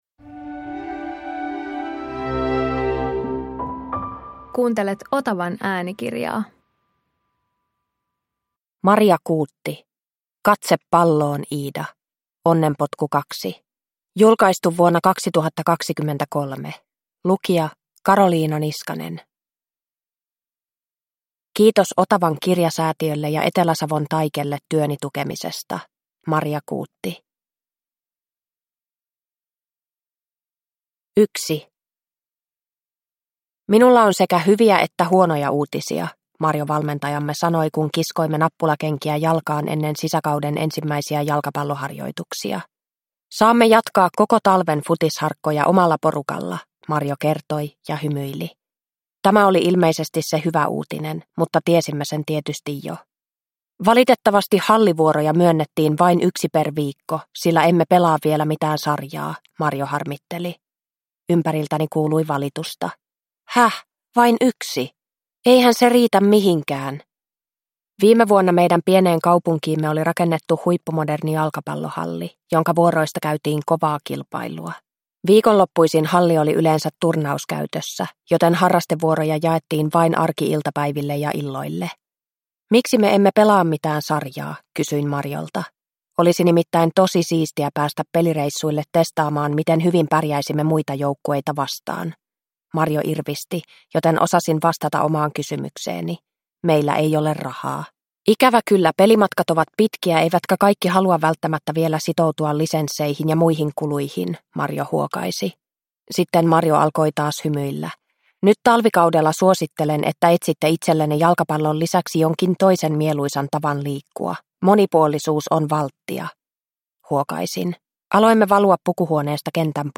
Katse palloon, Iida! – Ljudbok